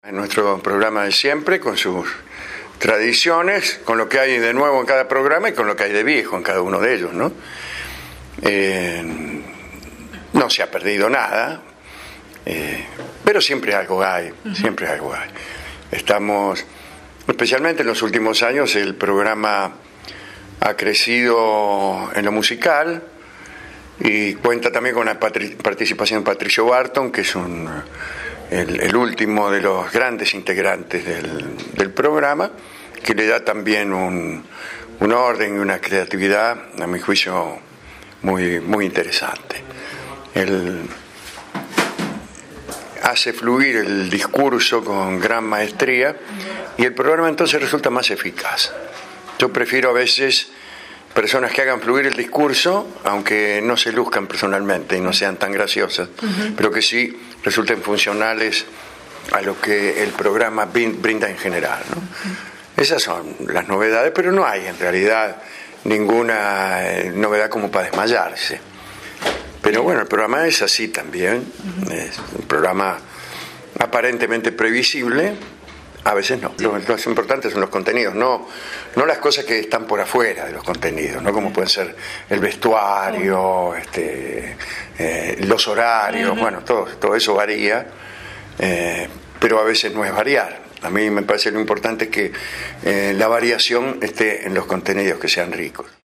Los días 17 y 18 de noviembre Alejandro Dolina presentó sus funciones en el teatro El Galpón. La concurrencia de público fue notable, se vendieron todas las entradas para todas las funciones.